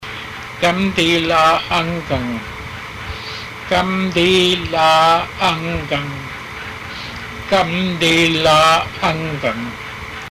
Master Fluent Speaker